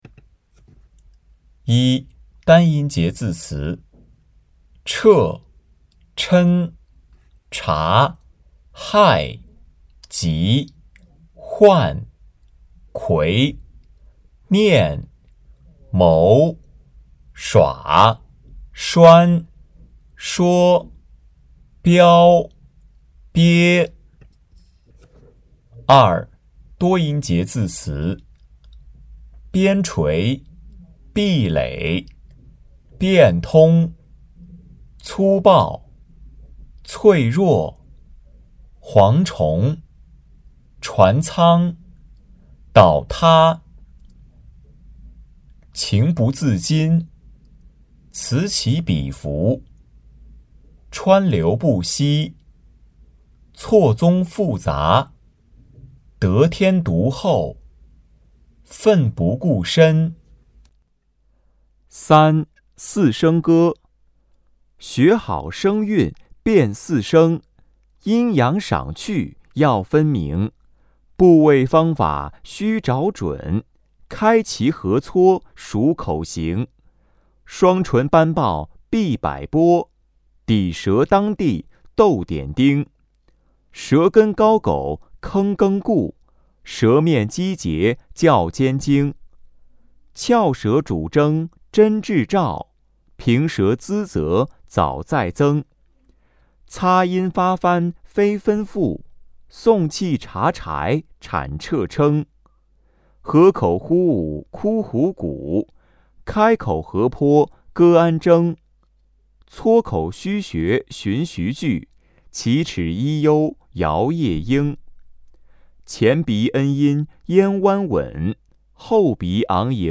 感谢积极参与此次“领读周周学”课件录制的“生活好课堂”朗读专业志愿者！